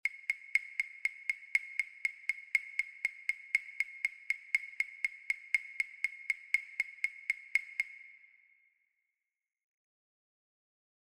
A continuación estarán los audios para escuchar los resultados sonoros de cada línea rítmica.
OBtnAzEjxXX_Base-ritmica-Alegre.mp3